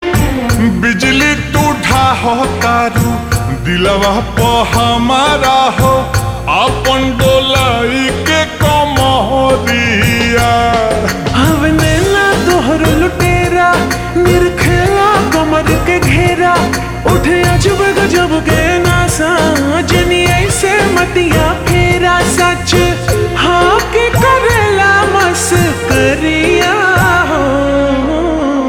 (Slowed + Reverb)